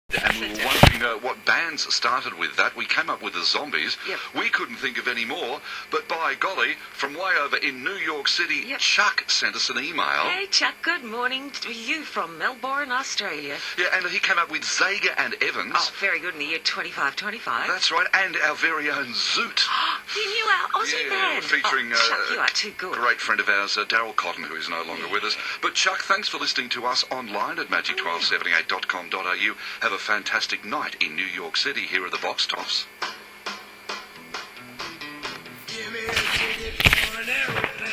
One of the stations I enjoy listening to at work is Magic 1278, a classic oldies station from Melbourne, Australia.